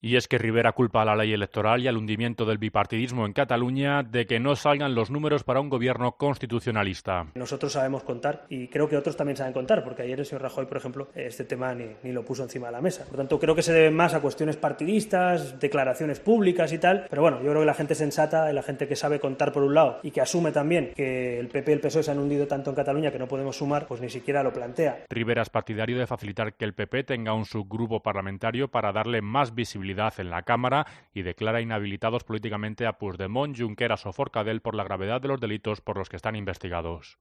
En rueda de prensa en el Congreso, Rivera ha lamentado que el "hundimiento del bipartidismo" del PP y el PSOE, así como una "maldita" ley electoral, impida que Ciudadanos pueda sumar para formar Gobierno, pero ha defendido que al menos debe tener una representación en la Mesa del Parlament acorde con su victoria en las elecciones del 21D.